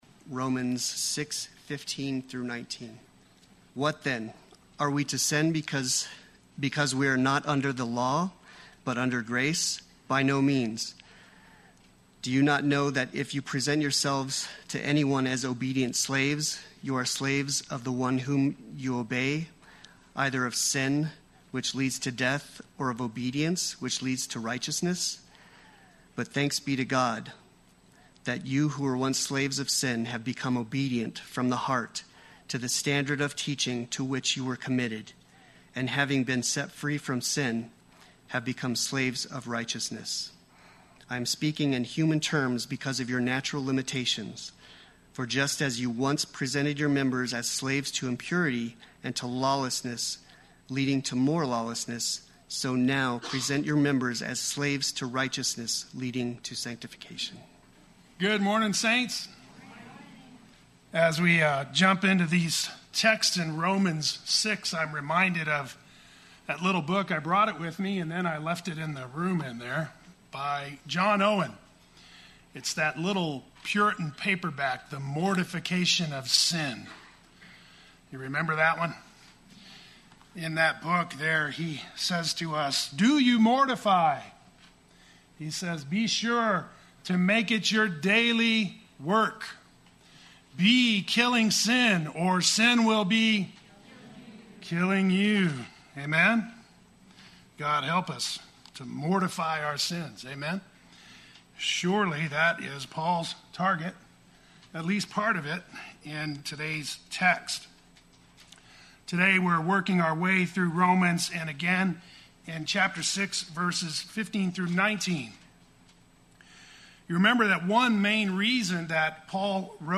Play Sermon Get HCF Teaching Automatically.
Free from Sin and Slaves to Righteousness Sunday Worship